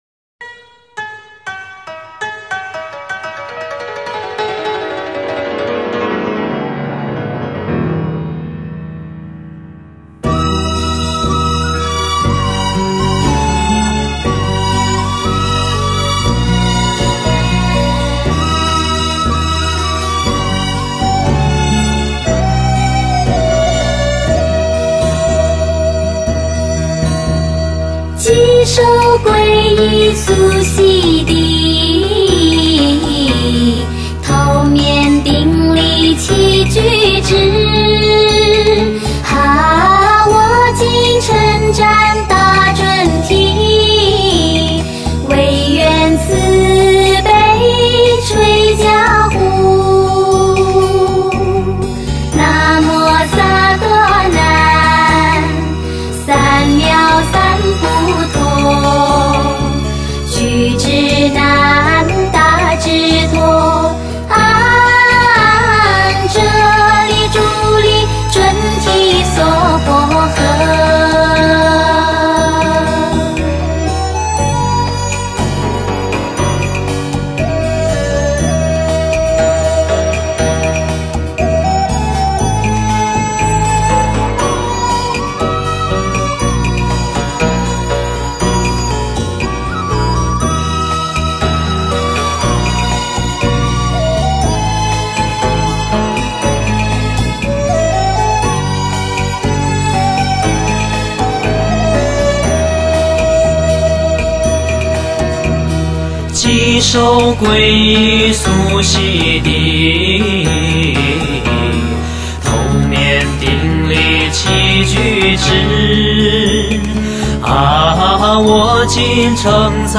[15/2/2010]特别好听的男女轮唱（愿有缘得之并受持者，人人平安，家家幸福）：佛母准提神咒